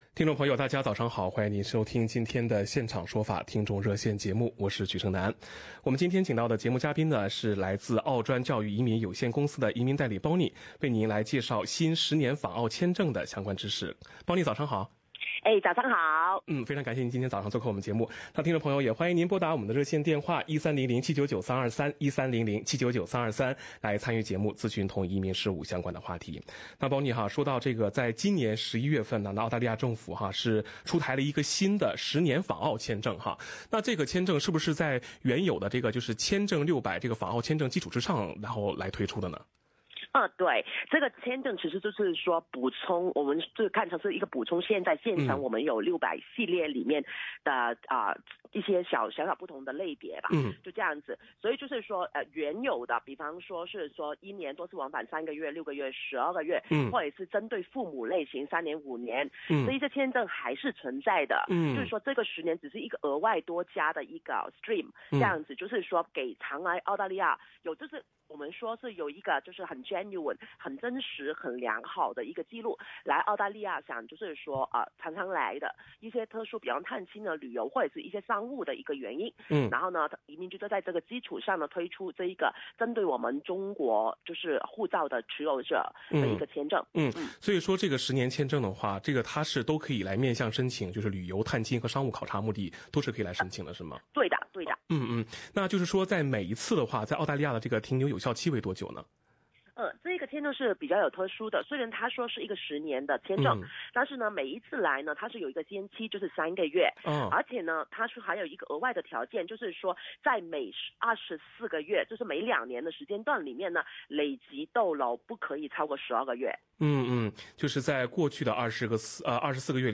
《现场说法-听众热线》逢周二上午8点半播出，法律和移民专家现场普法，指点迷津。